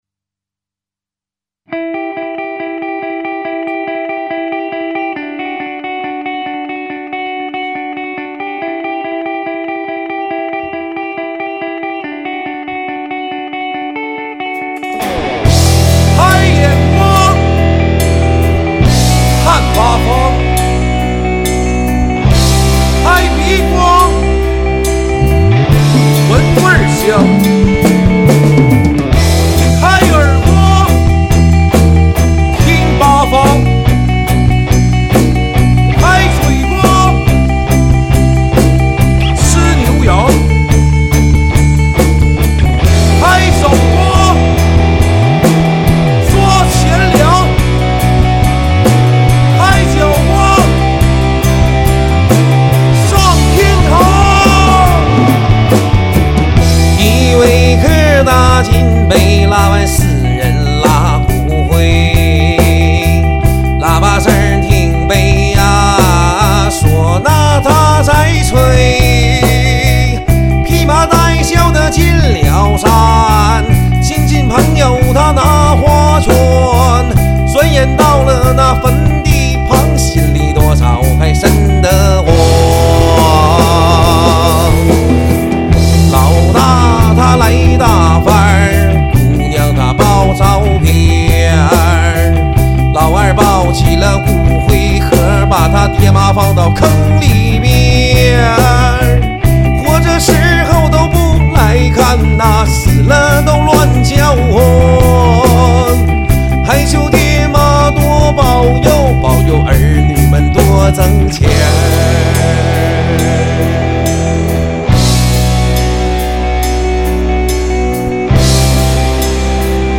吉他
鼓手
贝斯
唢呐